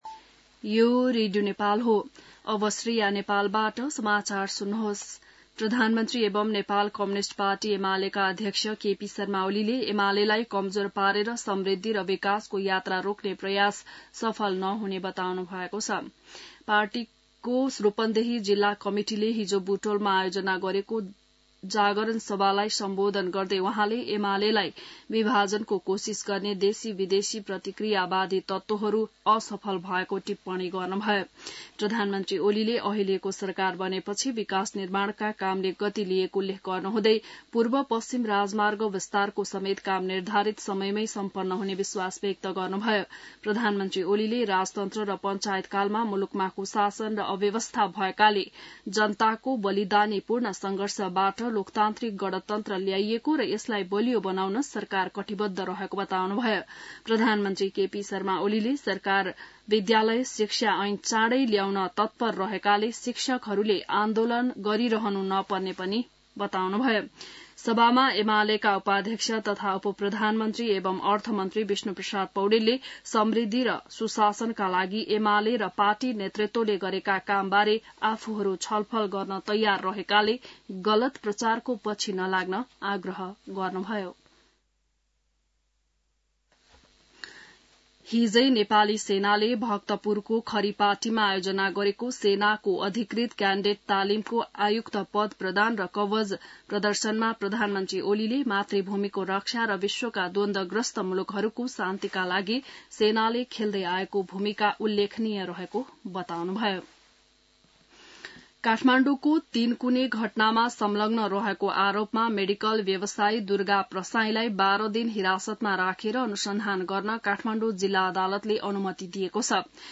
An online outlet of Nepal's national radio broadcaster
बिहान ६ बजेको नेपाली समाचार : ३० चैत , २०८१